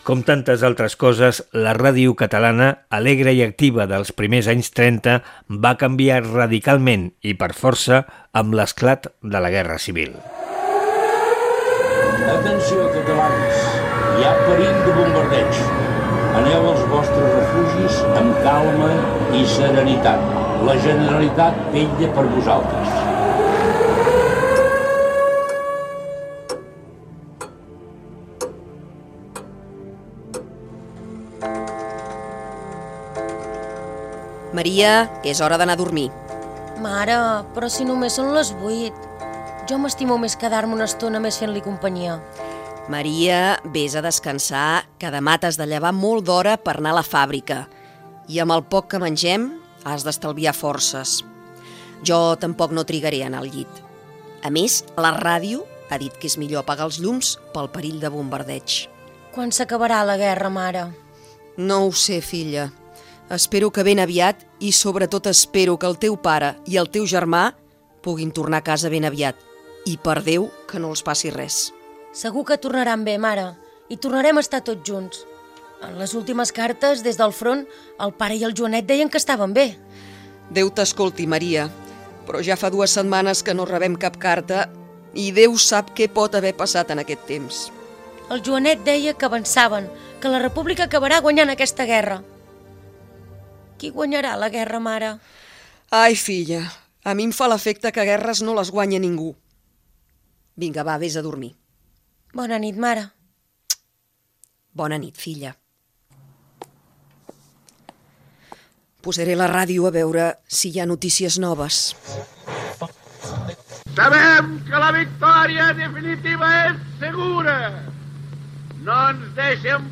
Emès amb motiu del Dia Mundial de la Ràdio 2024. La ràdio en temps de guerra civil i postguerra. Recreació de Ràdio Associació de Catalunya (veu Teodor Garriga), dramatització dels dies de la Guerra civil. Fragment d'un "Diario hablado" de RNE i del concurs "Sal Costa".
Divulgació
FM